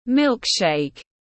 Sữa lắc tiếng anh gọi là milk shake, phiên âm tiếng anh đọc là /ˈmɪlk.ʃeɪk/
Milk shake /ˈmɪlk.ʃeɪk/